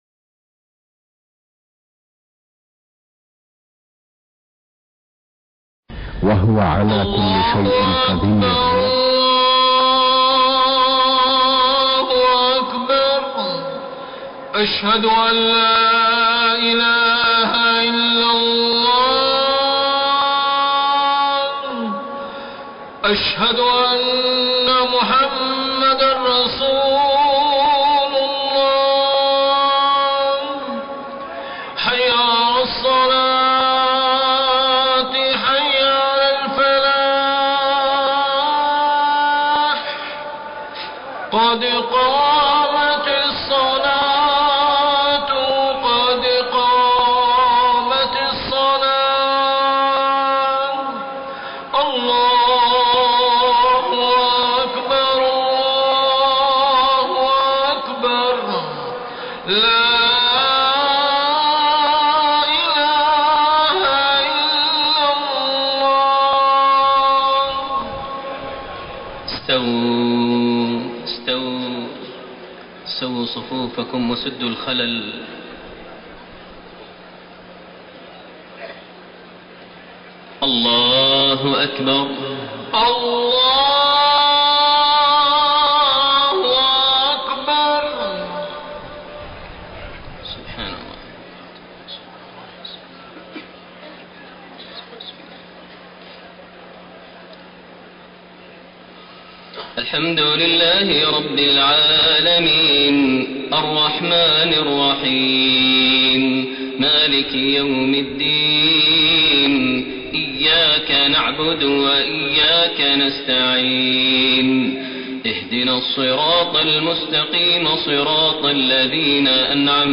صلاة الفجر 11 ذو الحجة 1432هـ من سورة الاحزاب 56-73 > 1432 هـ > الفروض - تلاوات ماهر المعيقلي